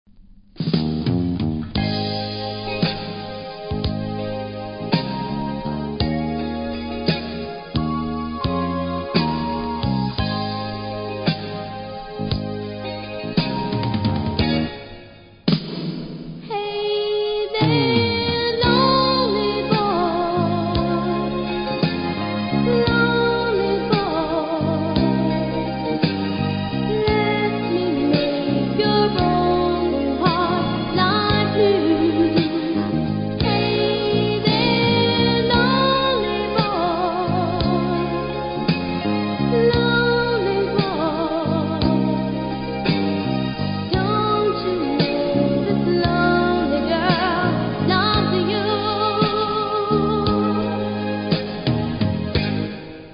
多少うすキズありますが音には影響せず良好です。